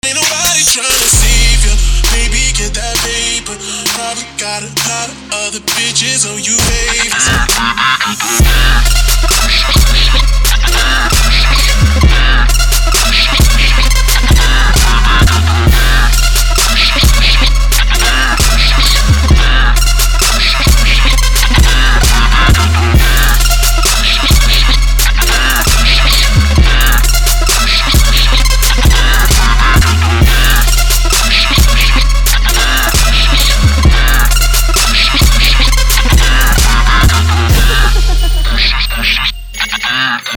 • Качество: 320, Stereo
громкие
атмосферные
Trap
пугающие
низкий мужской голос
Стиль: dark trap